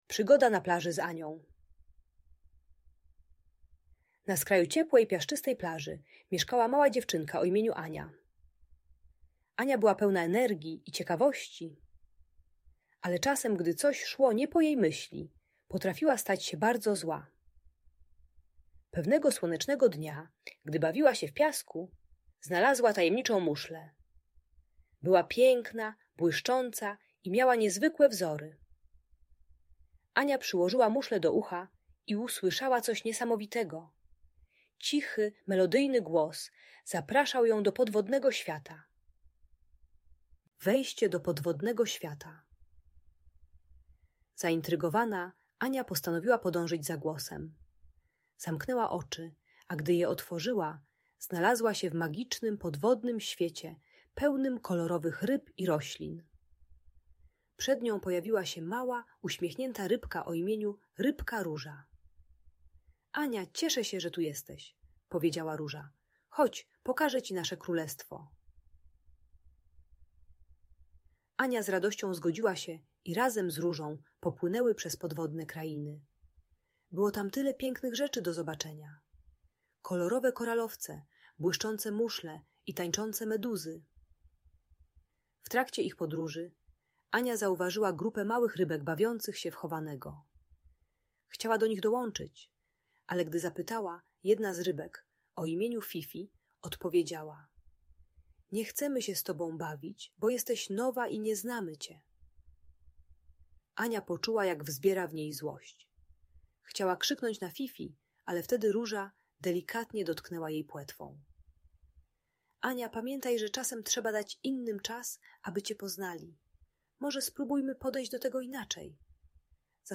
Przygoda Ani na Plaży - Audiobajka